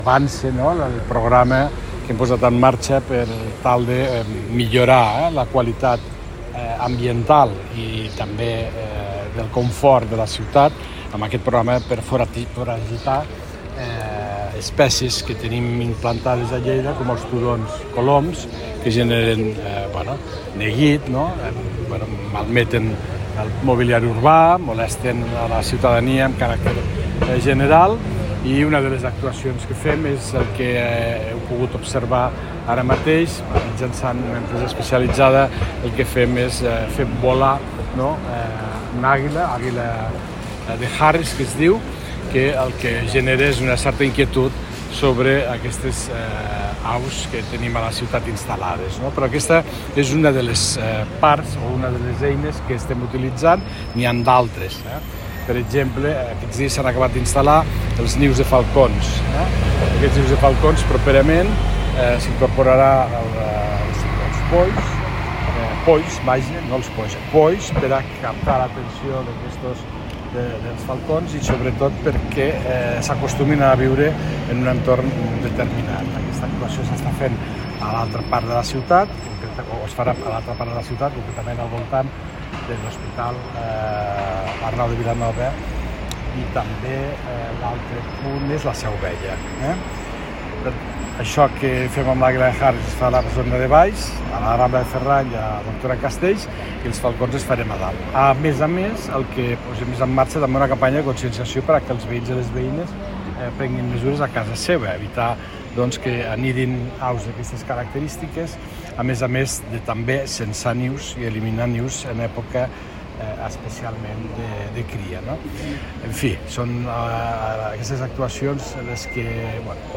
Tall de veu de l'alcalde de Lleida, Fèlix Larrosa, sobre el Pla de reforç d’actuacions de seguiment i control d’aus a la ciutat que ha posat en marxa la Regidoria de Sostenibilitat de la Paeria (2.6 MB)